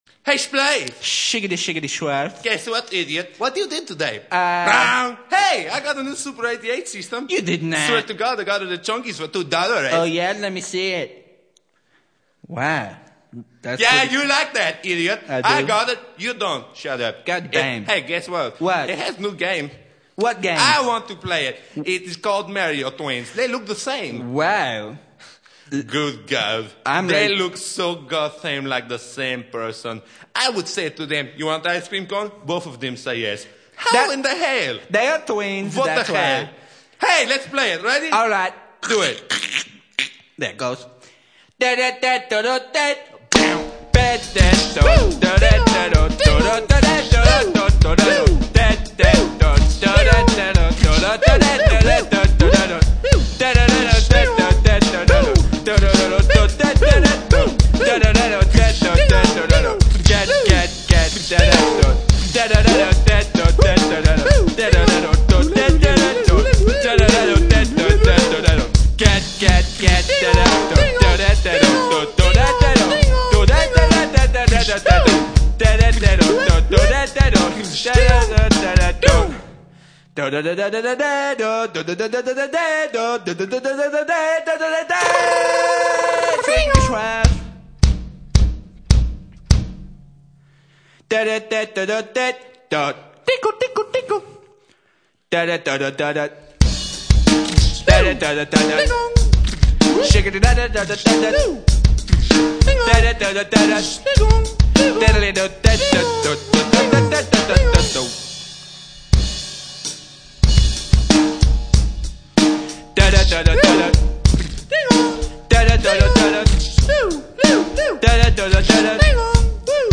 Comedy Song